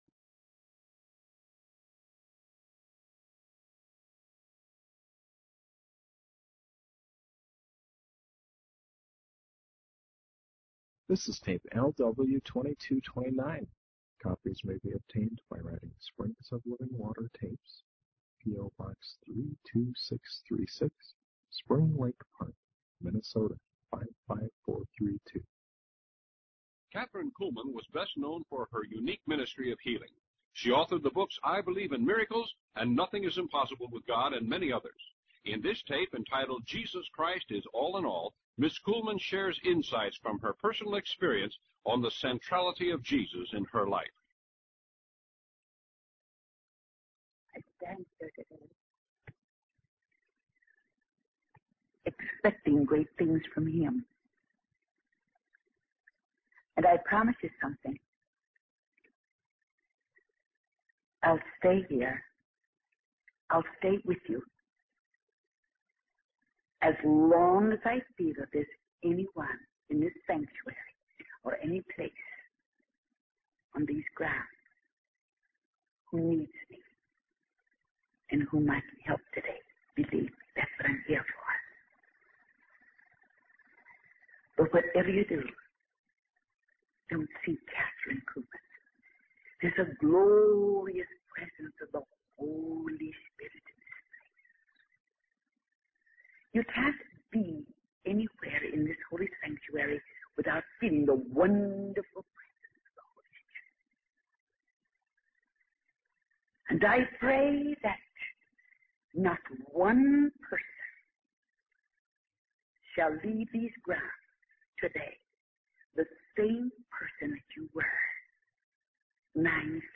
In this sermon, the preacher shares personal experiences and observations about the challenges and responsibilities of being called by God.